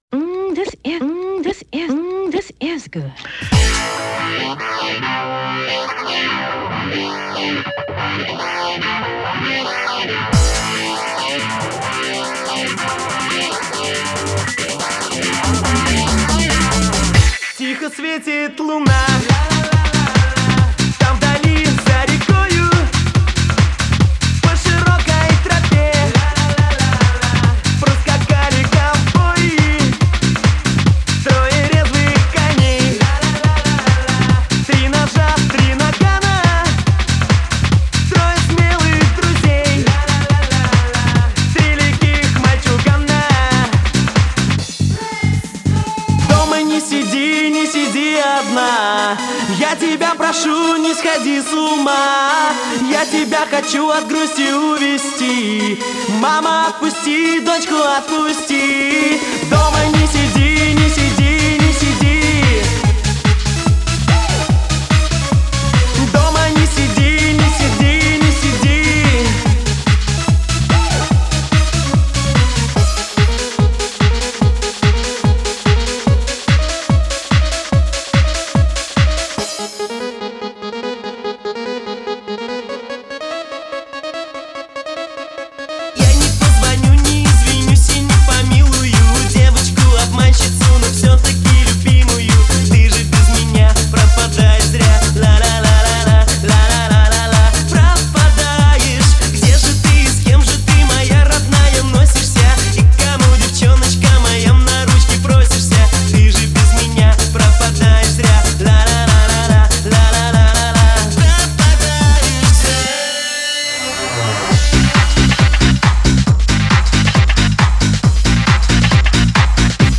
Трек размещён в разделе Русские песни / Танцевальная.